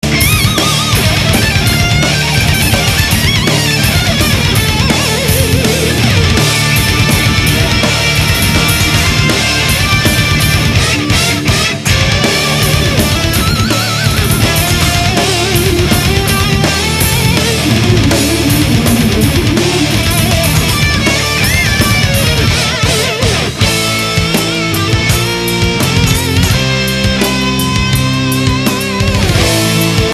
All the samples on this page are 22khz/44khz,16bit,stereo.